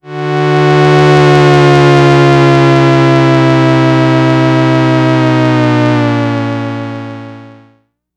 Moog Classic 01.wav